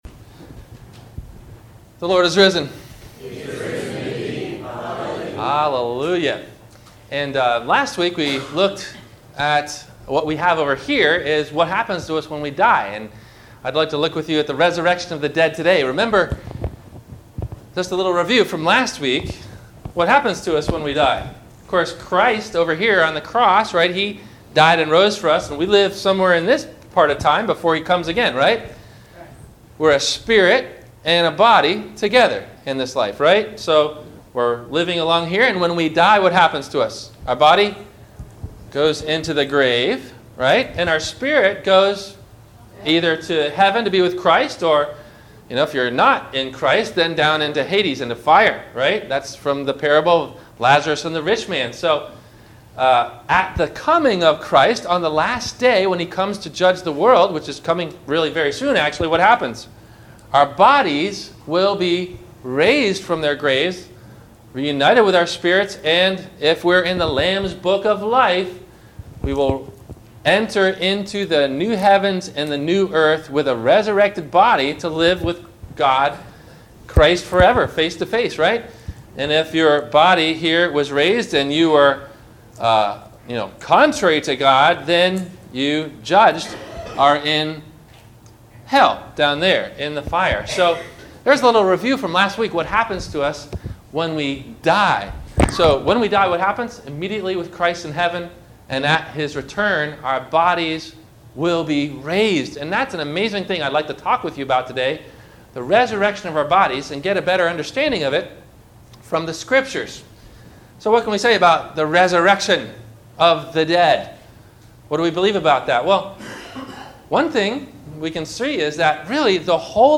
God's Lessons on the Resurrection of the Body - Sermon - April 17 2016 - Christ Lutheran Cape Canaveral